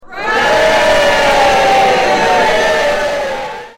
Hooray